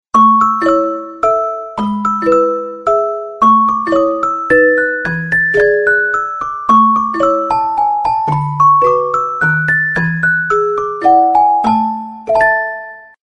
music_box.ogg